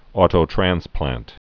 tō-trănsplănt)